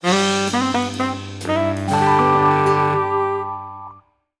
SC_Positive_Stinger_02.wav